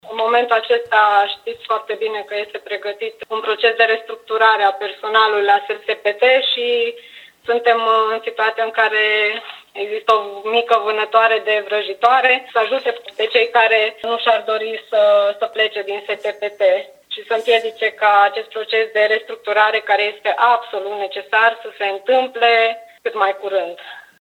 Liderul consilierilor USR, majoritari în Consiliul Local, Paula Romocean, spune că altul a fost motivul absenței colegilor ei.